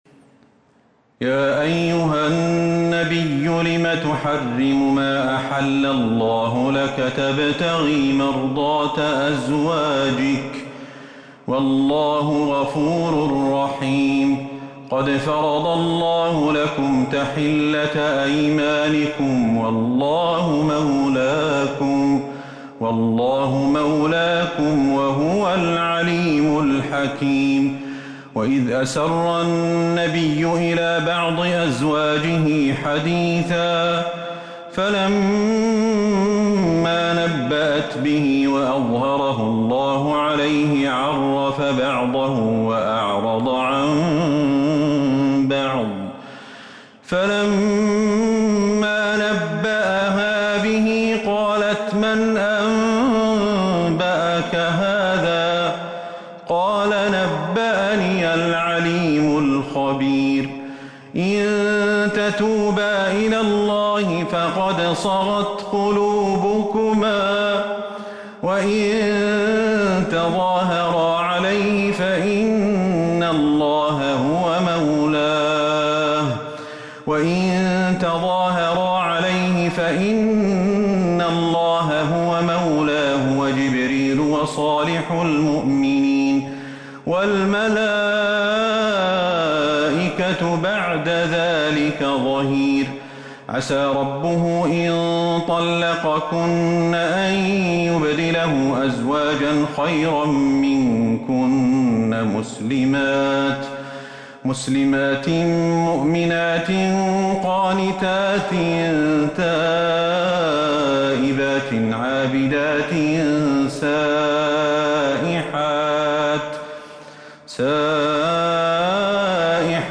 سورة التحريم Surat At-Tahreem من تراويح المسجد النبوي 1442هـ > مصحف تراويح الحرم النبوي عام 1442هـ > المصحف - تلاوات الحرمين